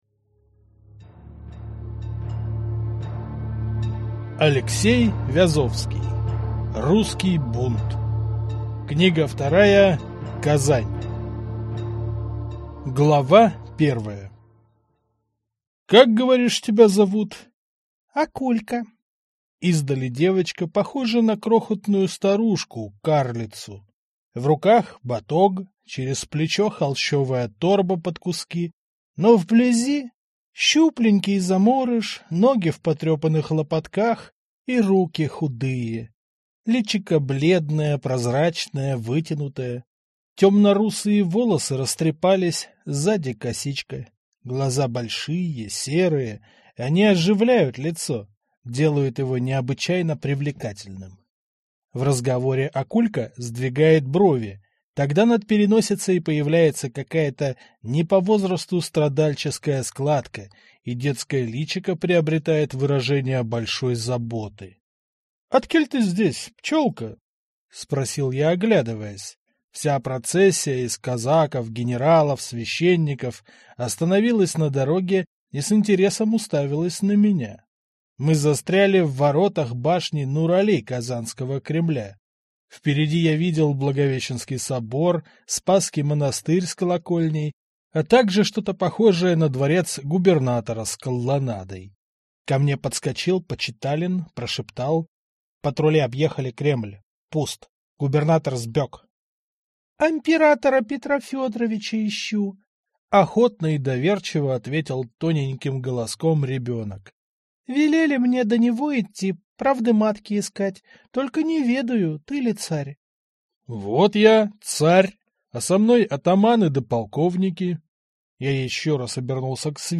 Аудиокнига Русский бунт. Казань | Библиотека аудиокниг